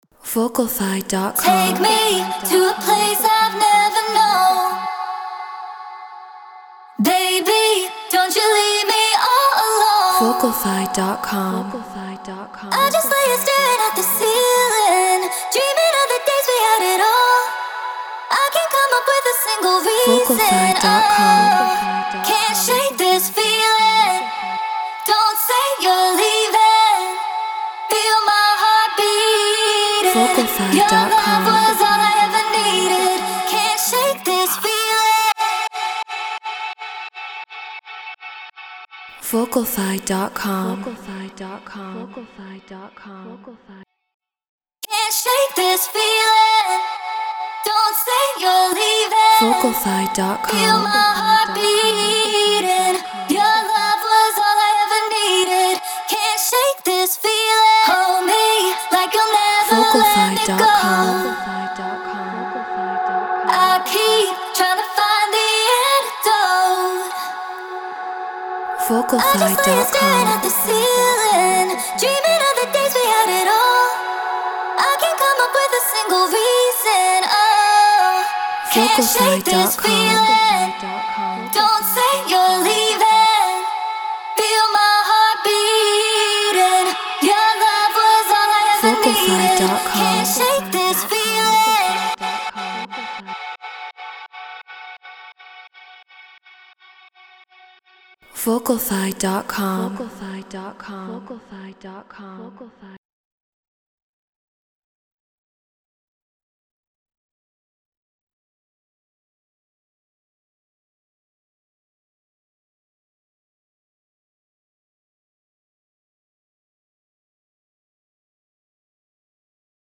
Hard Dance 170 BPM F#min